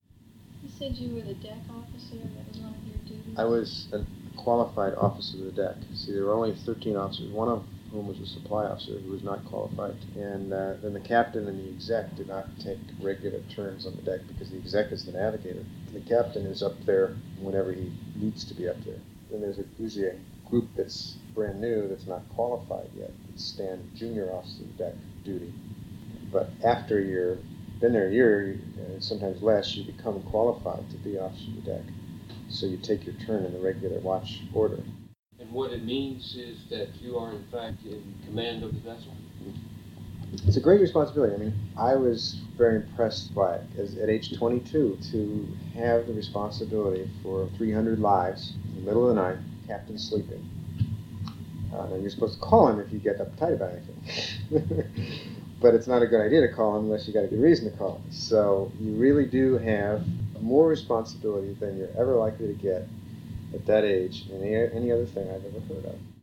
Oral Histories - The Pilothouse & Bridge - USS CASSIN YOUNG